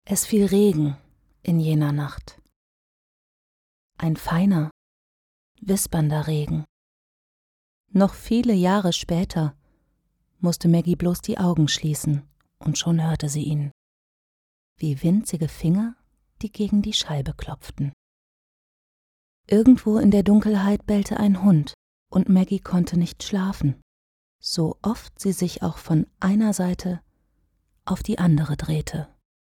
Sprecherin, Werbesprecherin, Hörspiel-Sprecherin,Mikrofonstimme
Kein Dialekt
Sprechprobe: Industrie (Muttersprache):